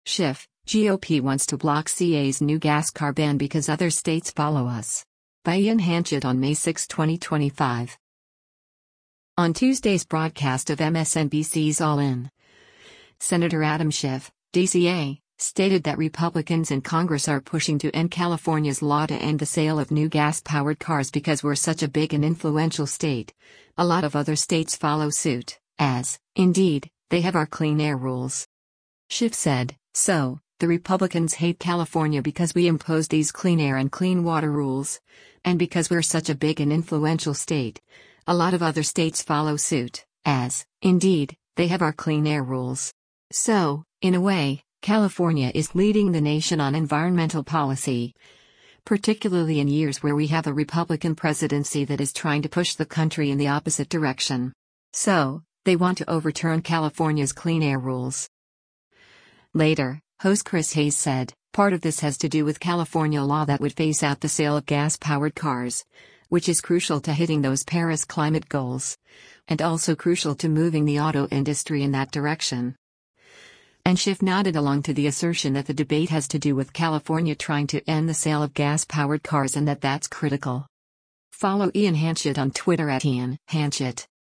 Video Source: MSNBC
On Tuesday’s broadcast of MSNBC’s “All In,” Sen. Adam Schiff (D-CA) stated that Republicans in Congress are pushing to end California’s law to end the sale of new gas-powered cars “because we’re such a big and influential state, a lot of other states follow suit, as, indeed, they have our clean air rules.”